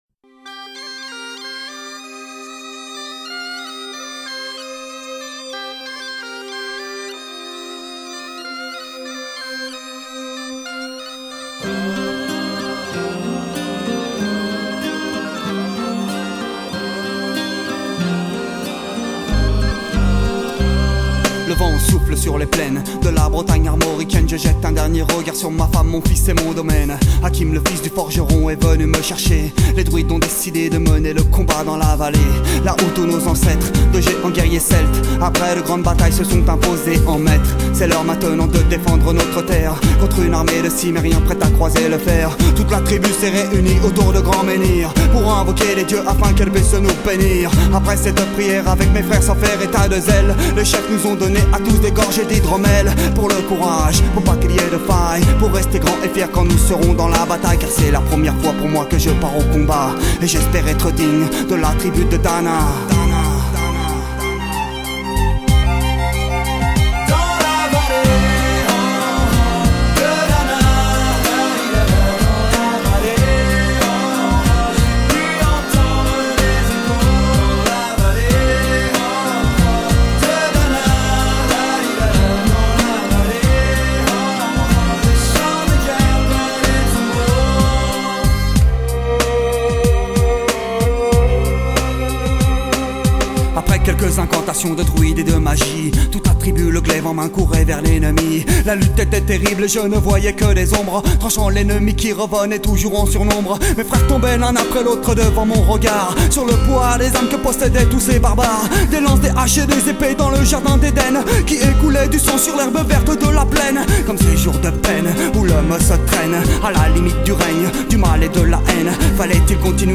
还是上面那支曲子 虽然很多大同小异的版本 但异数还是有的 来听听法国人的说唱